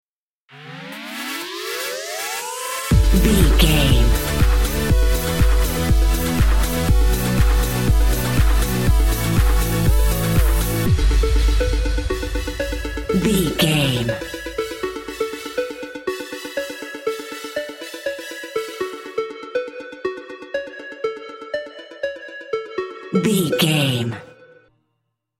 Aeolian/Minor
F#
groovy
uplifting
futuristic
driving
energetic
repetitive
synthesiser
drum machine
house
electro dance
instrumentals
synth leads
synth bass
upbeat